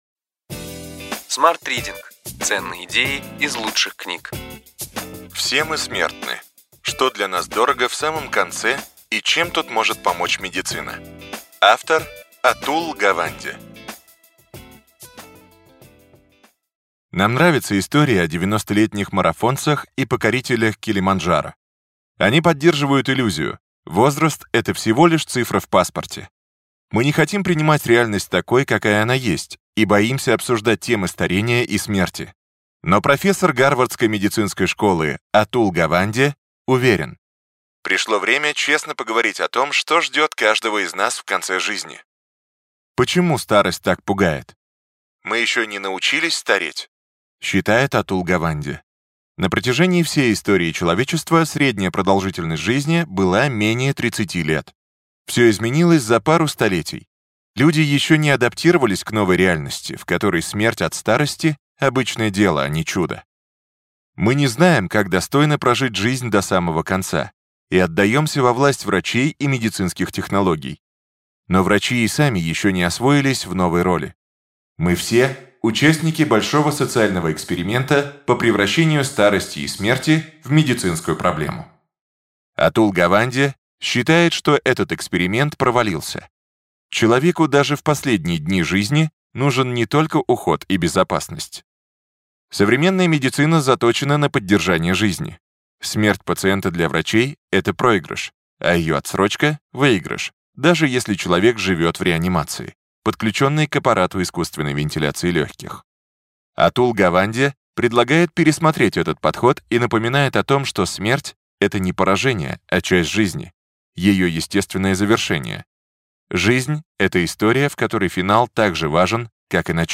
Аудиокнига Ключевые идеи книги: Все мы смертны. Что для нас дорого в самом конце и чем тут может помочь медицина. Атул Гаванде | Библиотека аудиокниг